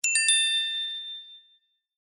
Pure bell.mp3